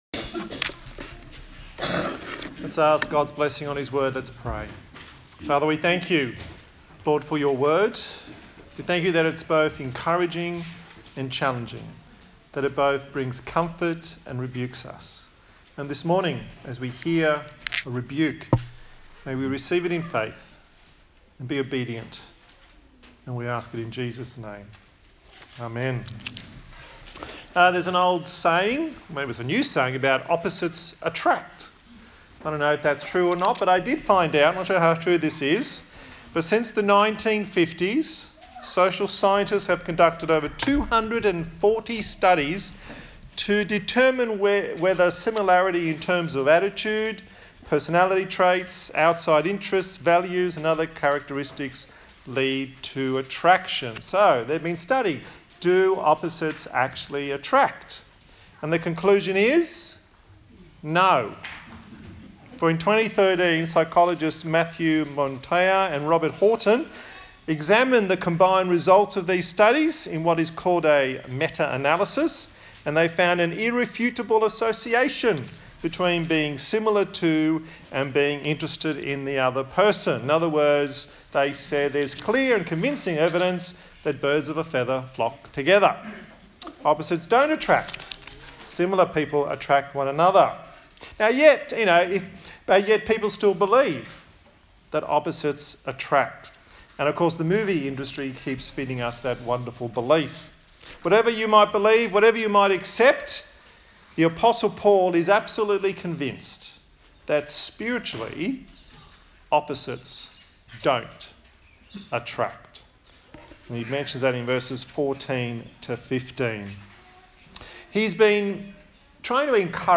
A sermon
Service Type: Sunday Morning